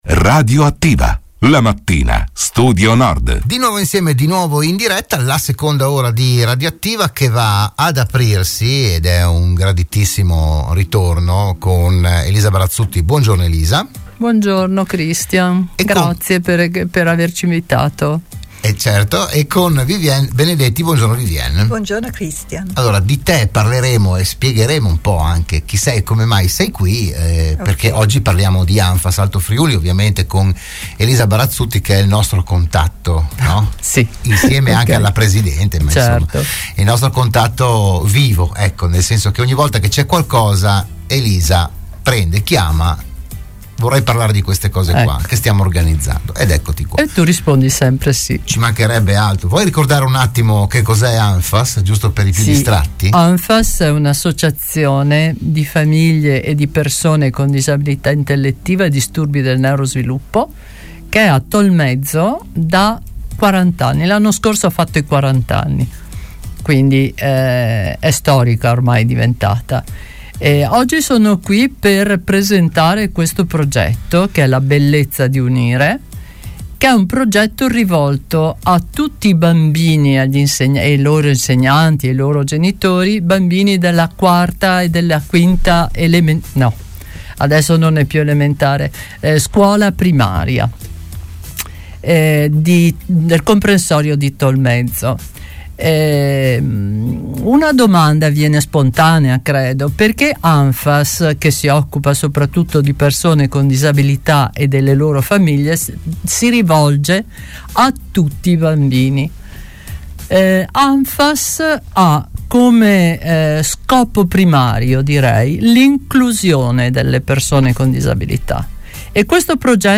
Se n'è parlato a Radio Studio Nord